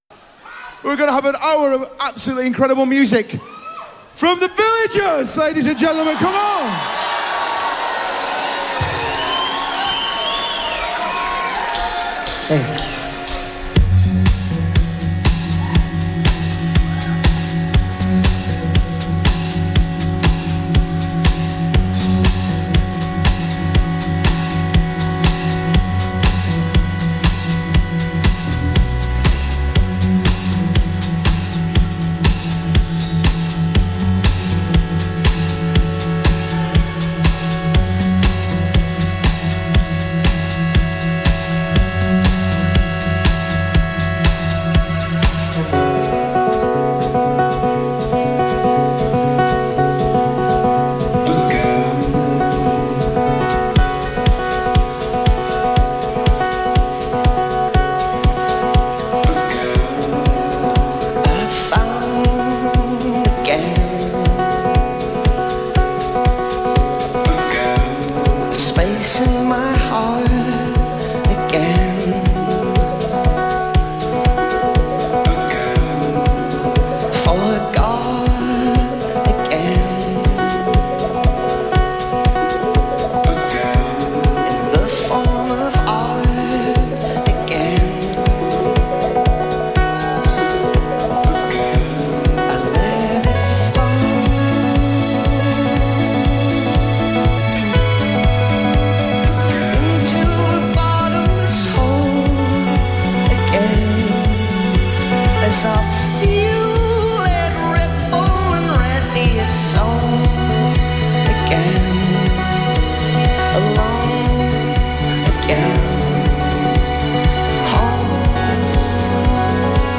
Recorded live
an Irish indie folk band
bass, upright bass
keyboards
drums, flugelhorn
harp